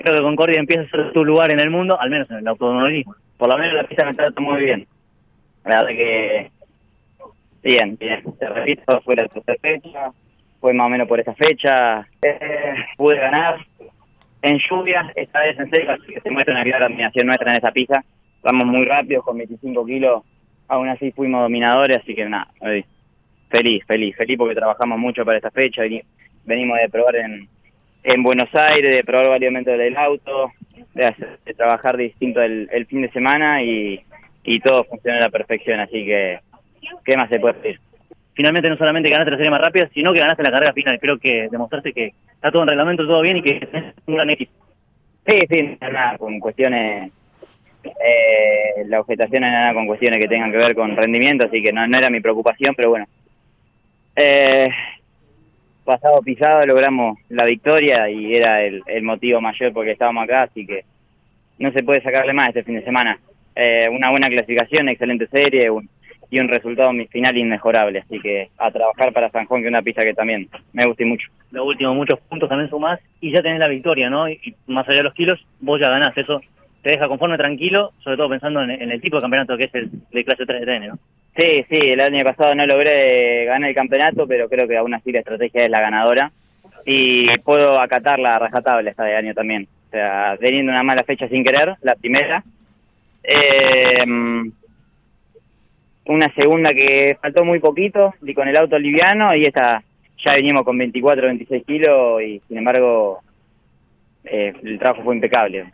En una nueva cobertura de una competencia del TN de este medio, tanto los tres mejores de la final de la divisional mayor, como así también el mejor cordobés posicionado, fueron aquí entrevistados.